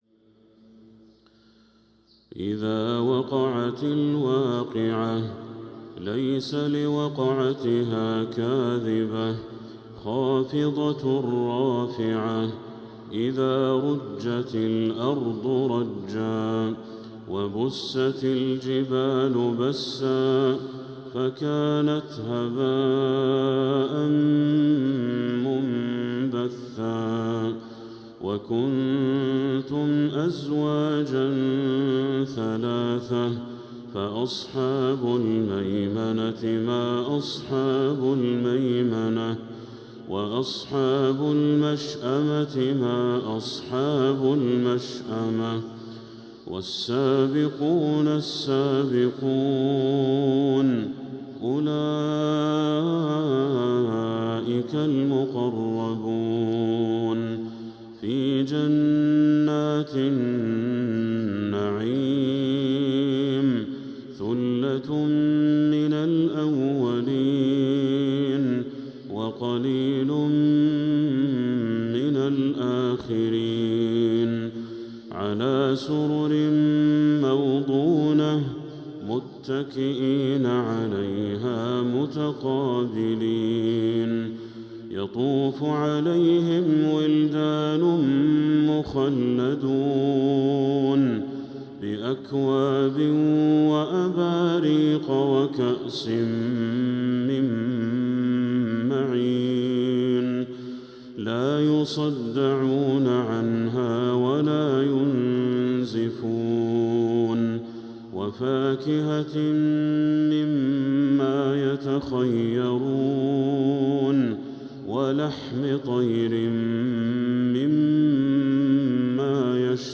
| سورة الواقعة كاملة للشيخ بدر التركي من المسجد الحرام | SuratAl-waaqi’ah Badr Al-Turki > السور المكتملة للشيخ بدر التركي من الحرم المكي 🕋 > السور المكتملة 🕋 > المزيد - تلاوات الحرمين